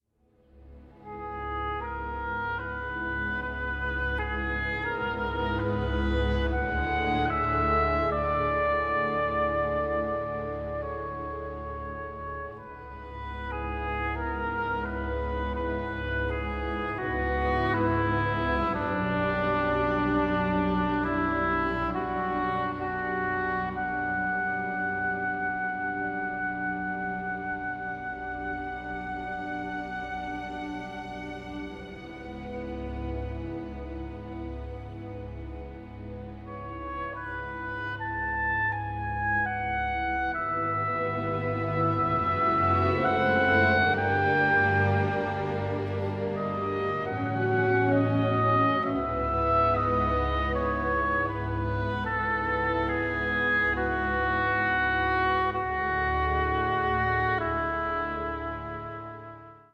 for Solo Oboe and Strings
oboe